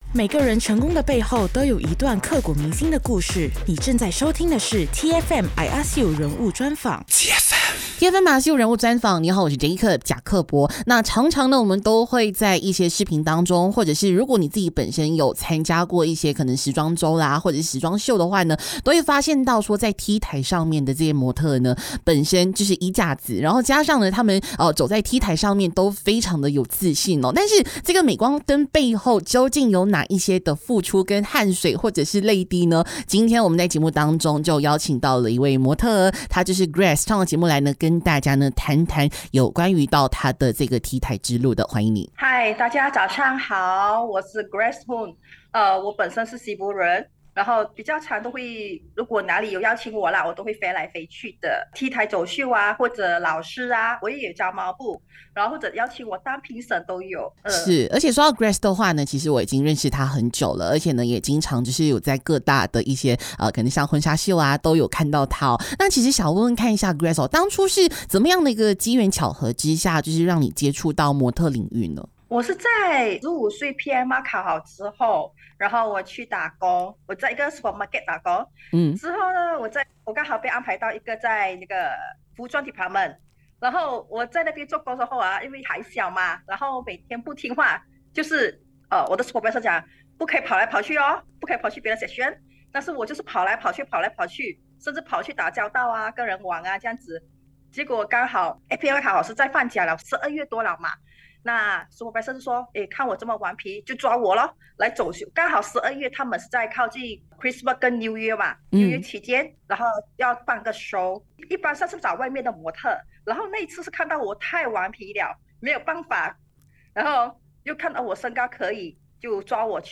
人物专访 资深模特儿